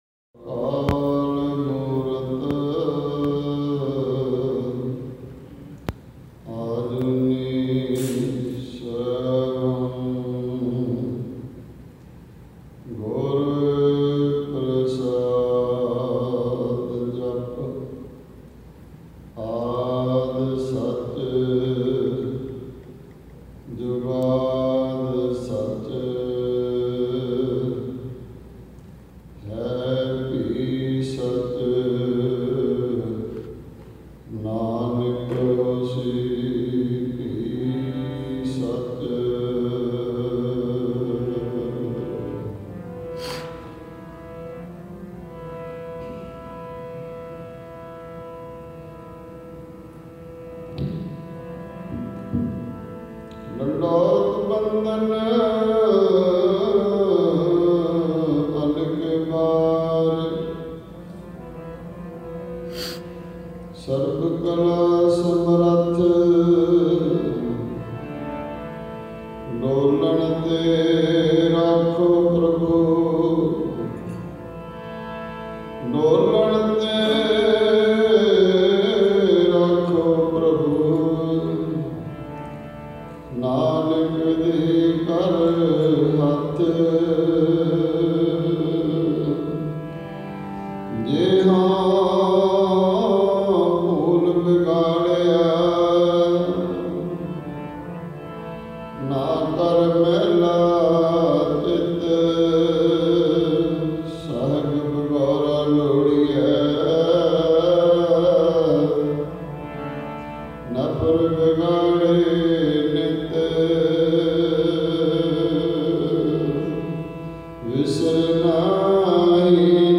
Roohani Kirtan – Nanaksar Gurdwara, Toronto – Day 2